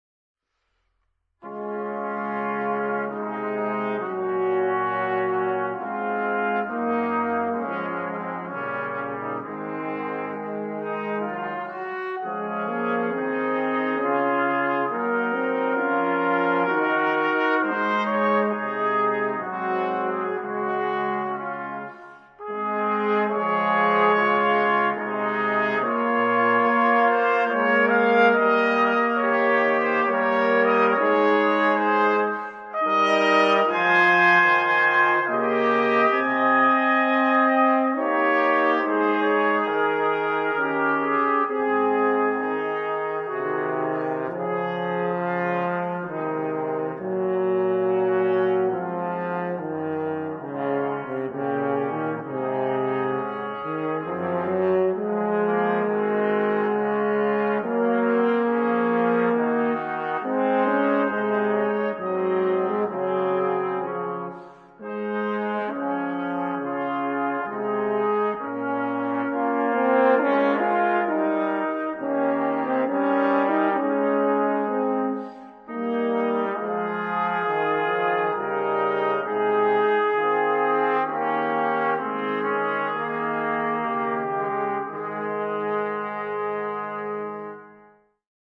Gattung: für Blechbläserquartett
Besetzung: Ensemblemusik für 4 Blechbläser